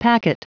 Prononciation du mot packet en anglais (fichier audio)
Prononciation du mot : packet